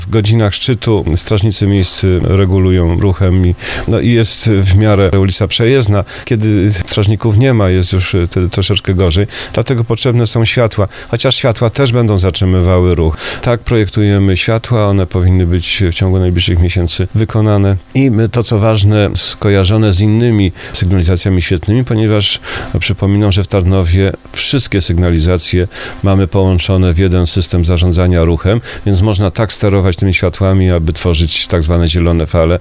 Prezydent Tarnowa Roman Ciepiela, na antenie RDN Małopolska, zapowiedział, że światła mogą się pojawić w tym miejscu w ciągu kilku miesięcy.